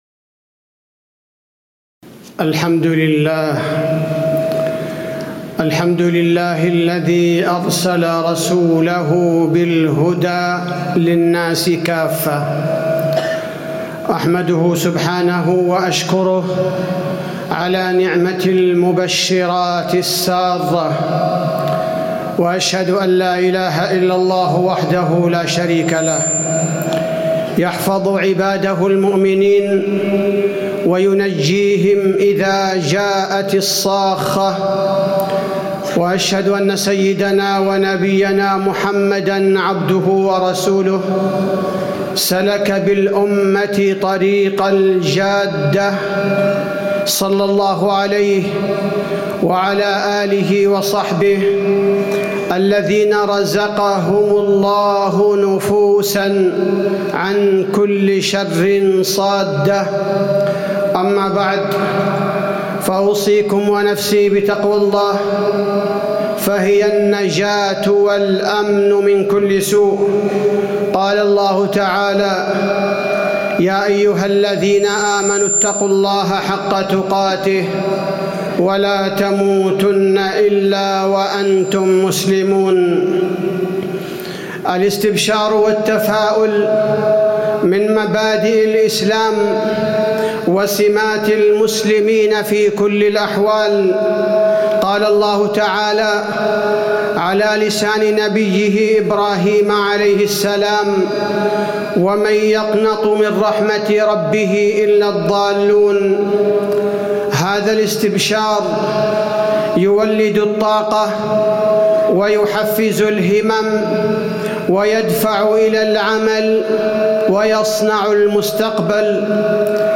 تاريخ النشر ٢٢ ربيع الثاني ١٤٣٨ هـ المكان: المسجد النبوي الشيخ: فضيلة الشيخ عبدالباري الثبيتي فضيلة الشيخ عبدالباري الثبيتي أسباب انتشار الإسلام